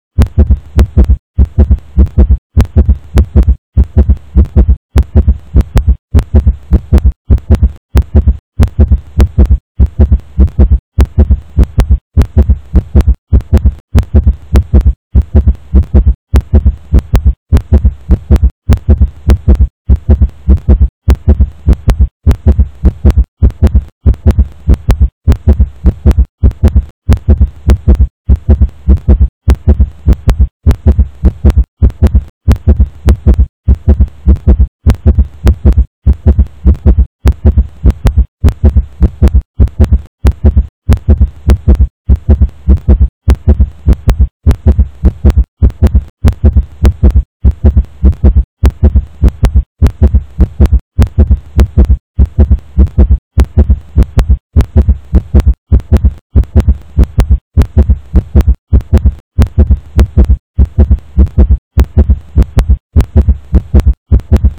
Reto Veterinario: Sonido Cardíaco
El sonido corresponde a un rottweiler, macho, de 42 kg, entrenado para defensa y muy activo, hace ejercicio físico de forma regular y frecuente, está en una condición corporal óptima con un gran desarrollo muscular. Se realizan controles de salud rutinarios de forma periódica y en esta ocasión se detecta un sonido cardíaco que llama la atención del veterinario.